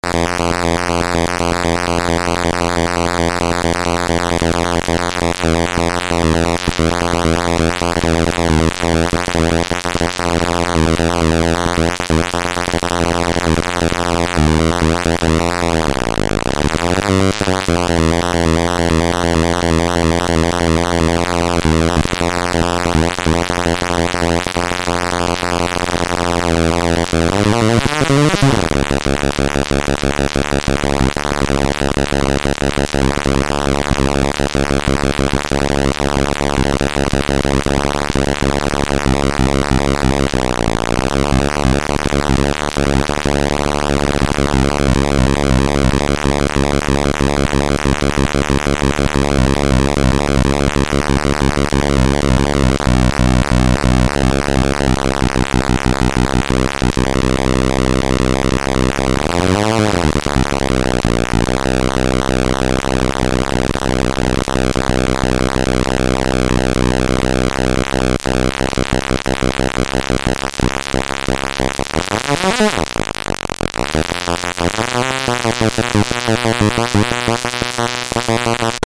edit VOICE this noisebox uses a dual 556 and it generates a single voice based on a pulse waveform with variable width.
demo hardware demo 1
REVIEW "cheap and fun to build! of course you to have to love those bleeps and other 8bits noises from the past."